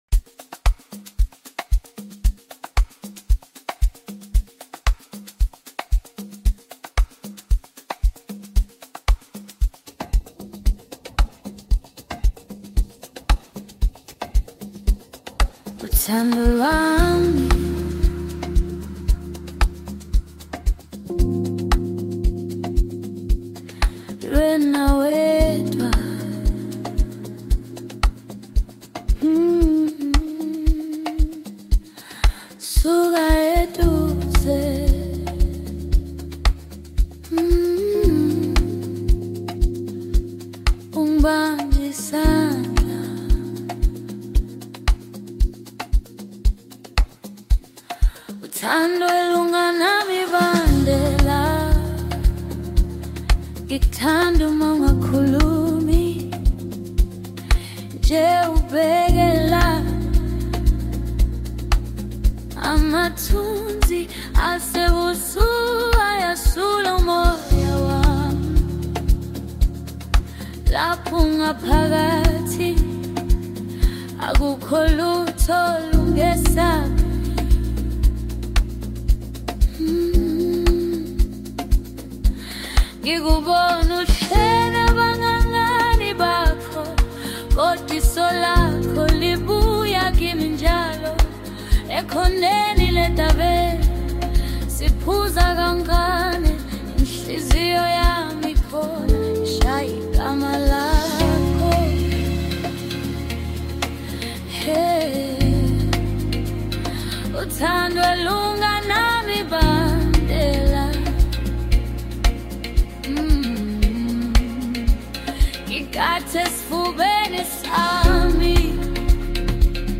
Home » Amapiano » DJ Mix
South African singer